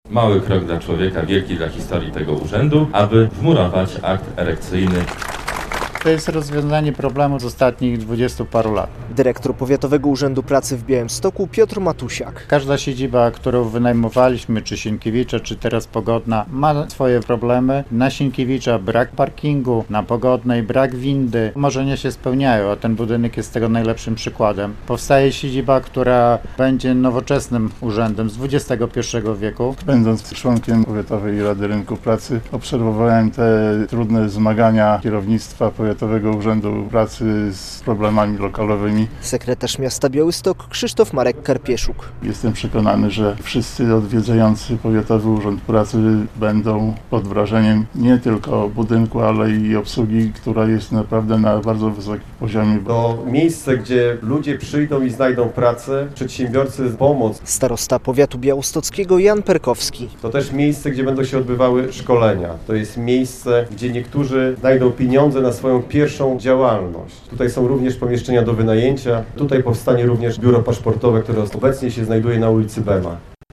Akt erekcyjny Powiatowego Urzędu Pracy - relacja